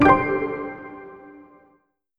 button-play-select.wav